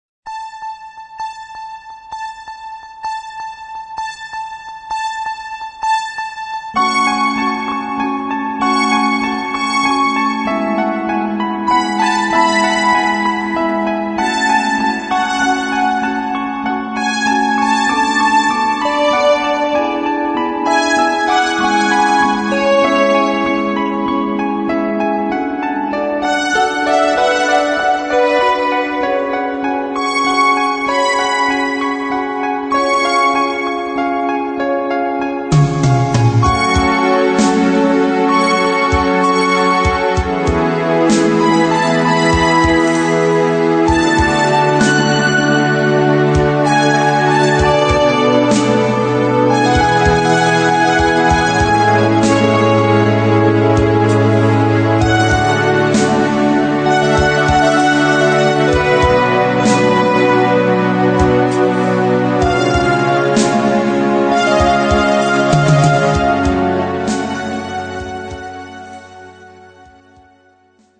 Рингтоны, мелодии из фильмов и сериалов
спокойные Без слов
мелодичные